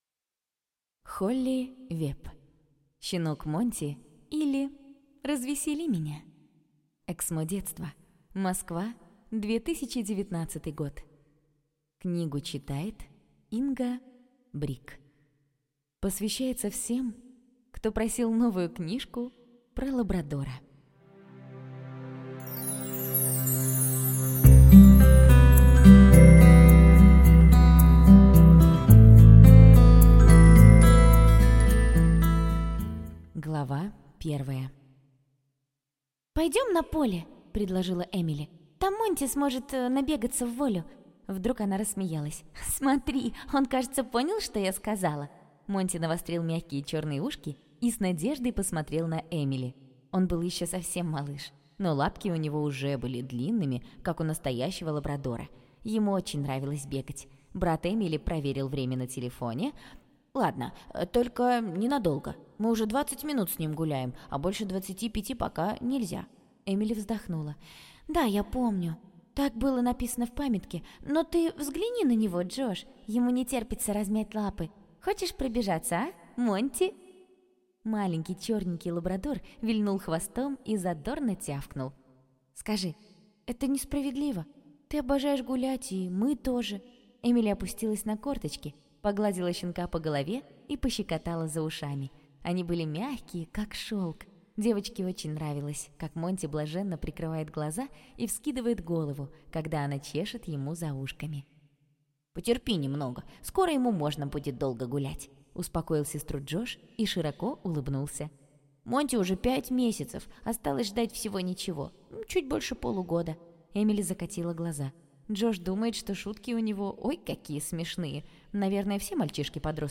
Аудиокнига Щенок Монти, или Развесели меня!